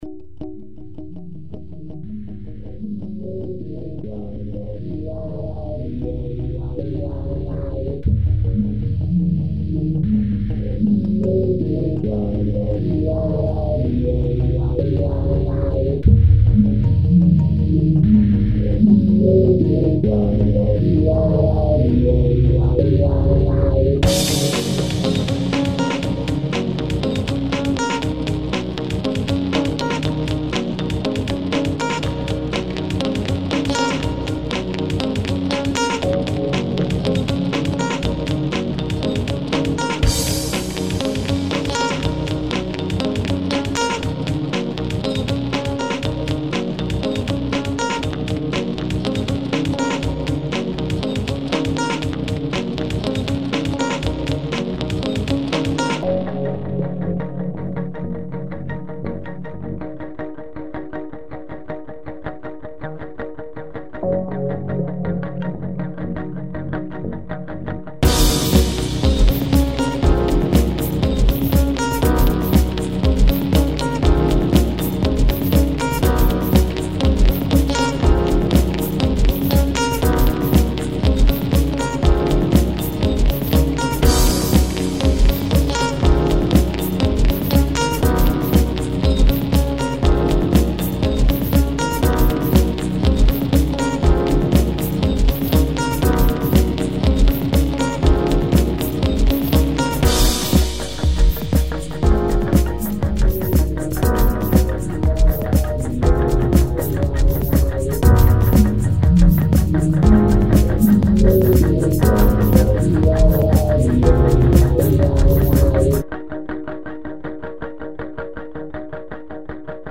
Guitares, samples, claviers, programmations, bricolages.
Saxophone soprano
à évolué vers un son plus électronique